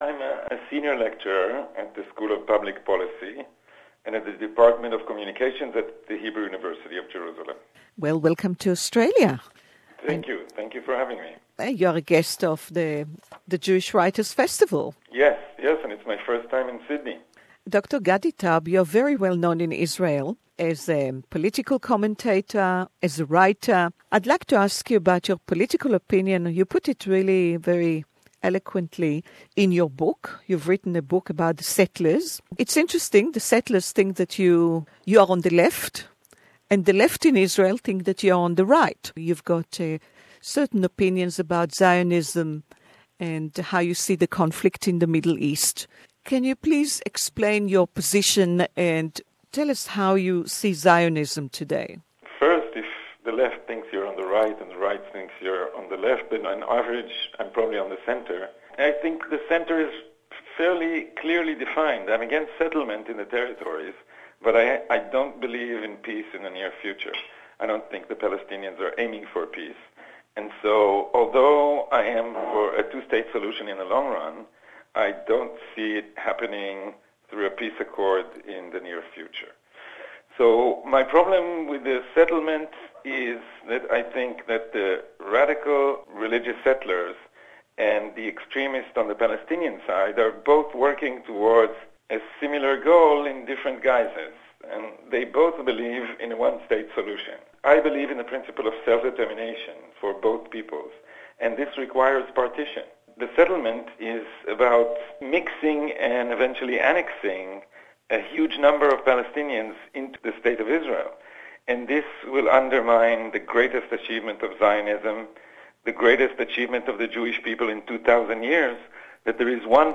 Dr Gadi Taub, Two States Solution the way to go, but not right now...Historian, Writer( English interview)